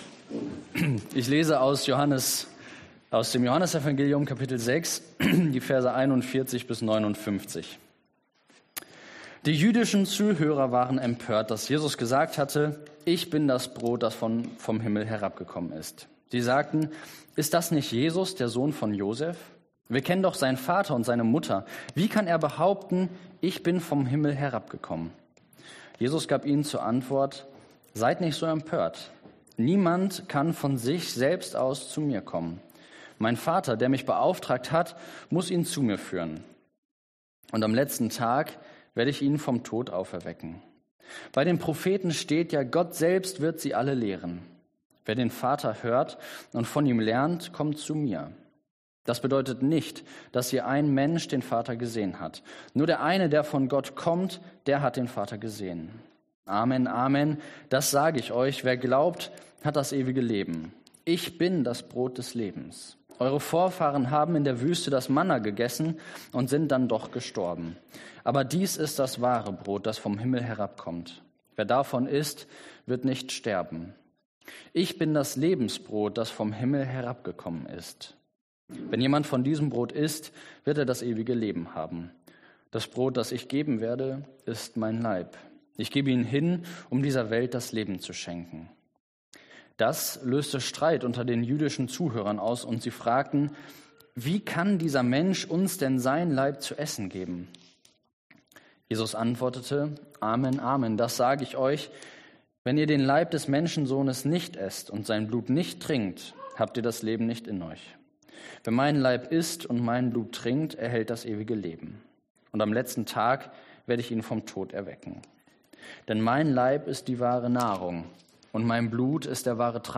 Passage: Johannes 6,41-59 Dienstart: Predigt